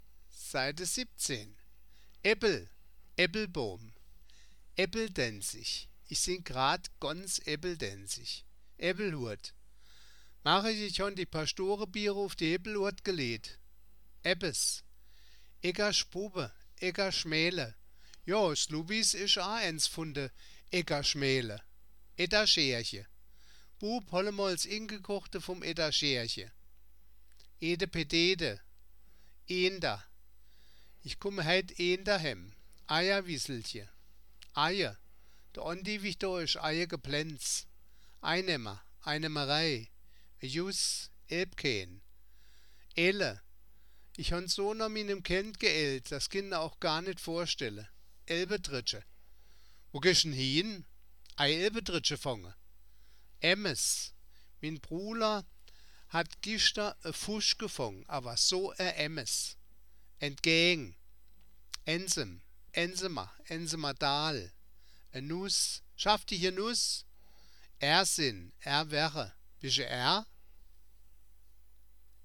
Das Wörterbuch der Ensheimer Mundart, Band I. Ensheim-Saar 1975